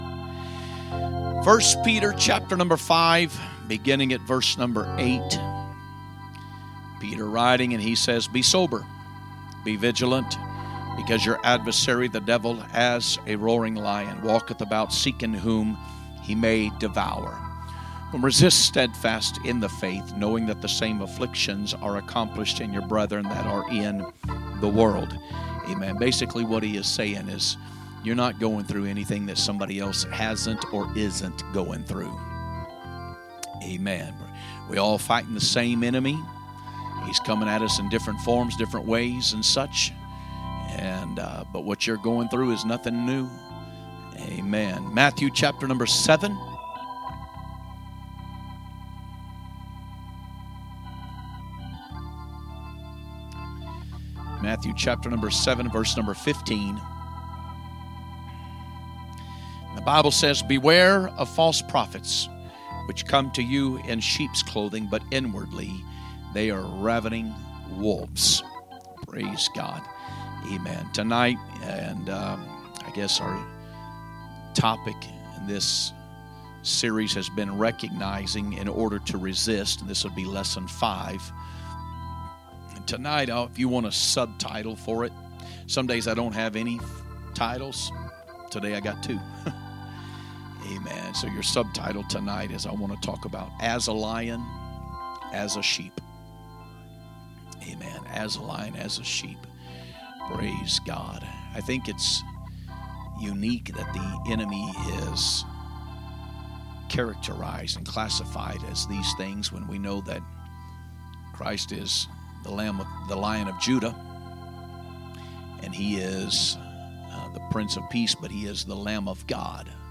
Wednesday Message - Lesson 5 - As a Lion As a Sheep
From Series: "2025 Preaching"